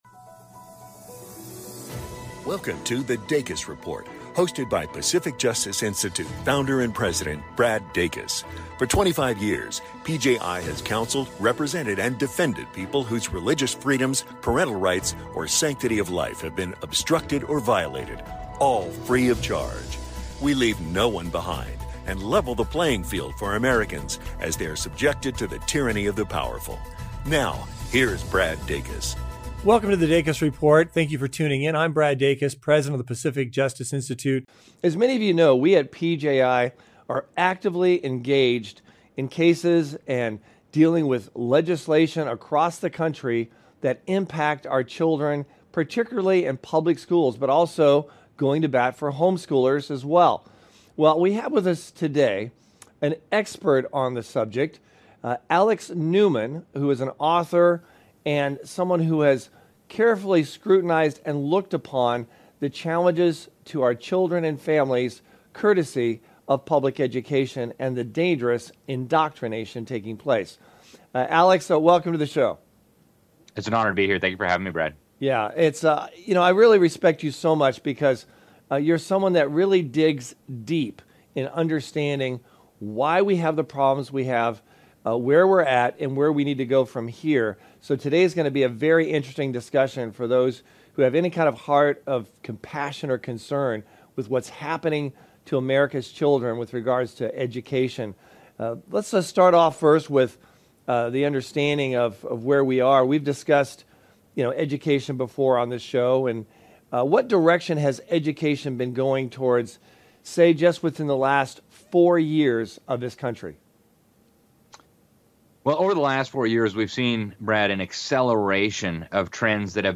Don’t miss this insightful discussion on the threats to your rights as a parent and what you can do to protect them. Tune in for an enlightening conversation that’s crucial for every concerned parent.